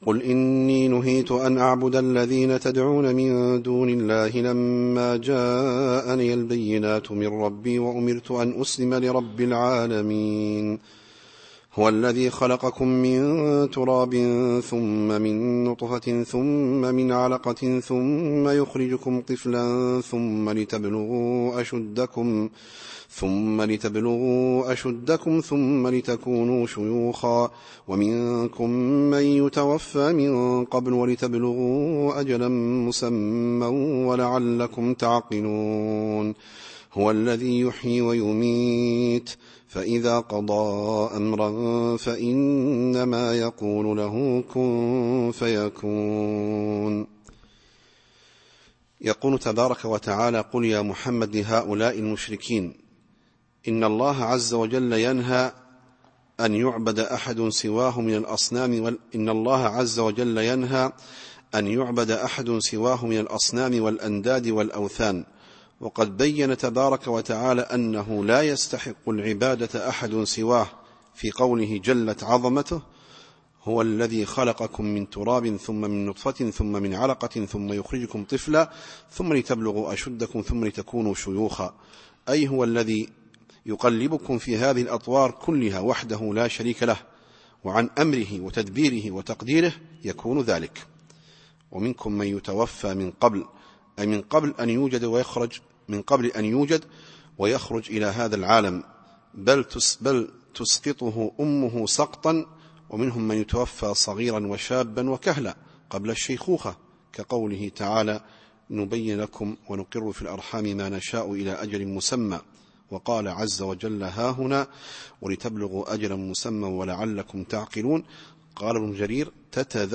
التفسير الصوتي [غافر / 66]